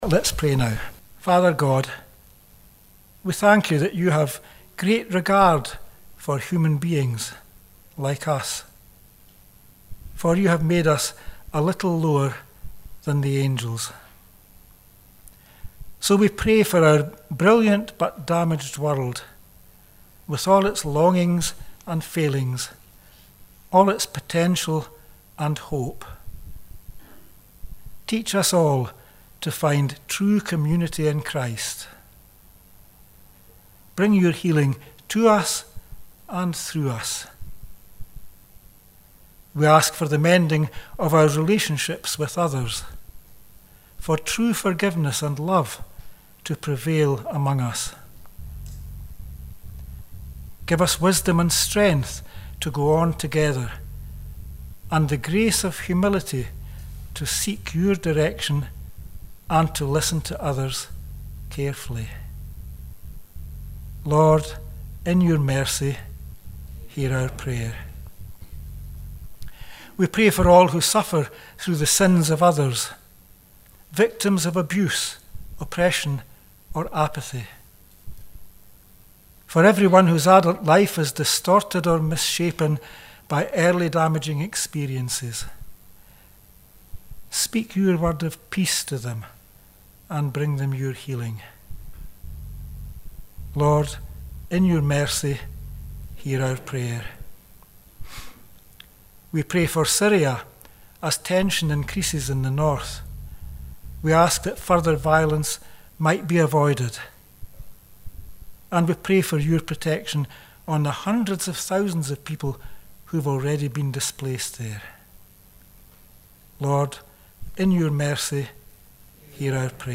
Joint Church of Scotland Service - 1 March 2020
prayerforothers.mp3